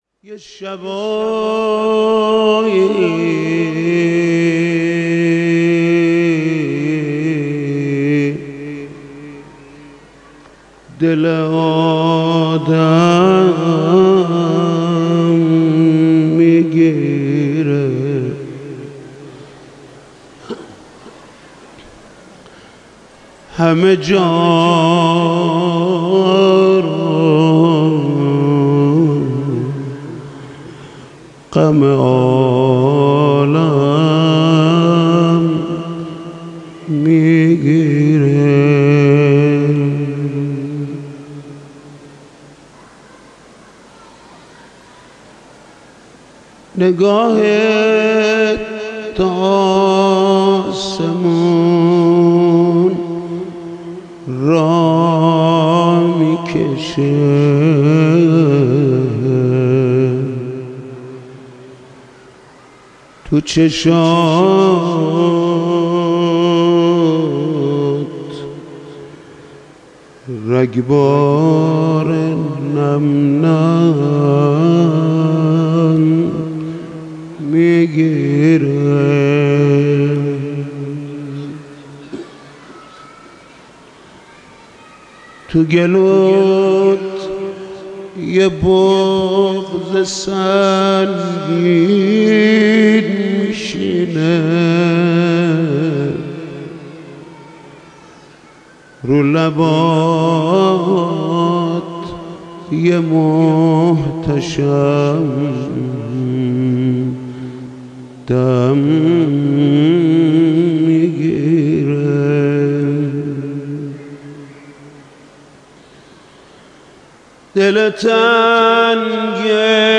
حاج محمود کریمی/گلچین مداحی های شب های ششم تا هشتم ماه مبارک رمضا97/مشهد مقدس